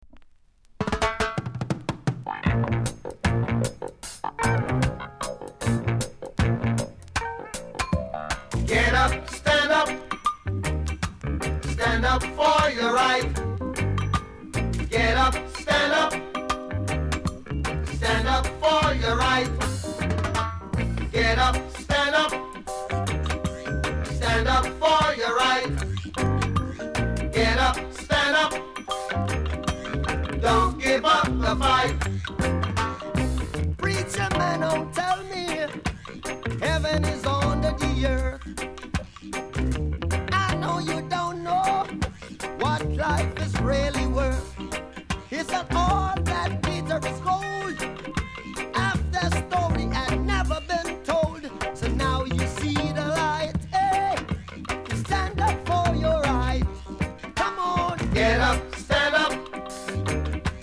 ※多少小さなノイズはありますが概ね良好です。